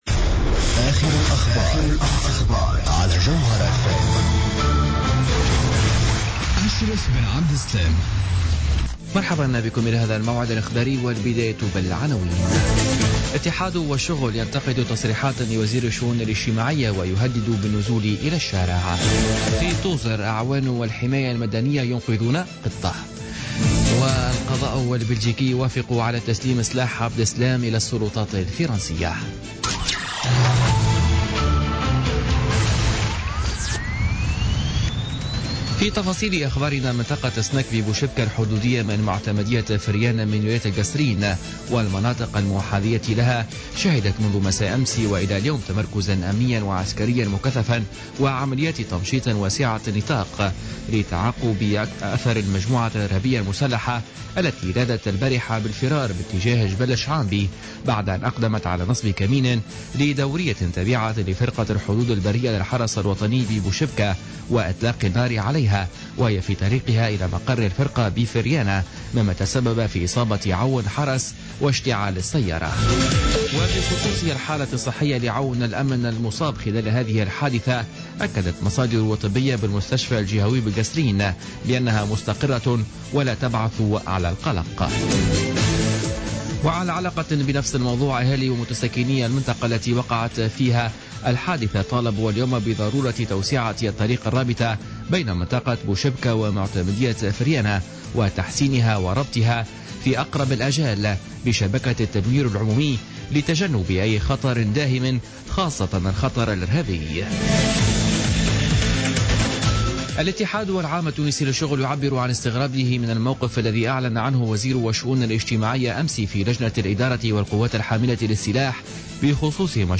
نشرة اخبار السابعة مساء ليوم الخميس 31 مارس 2016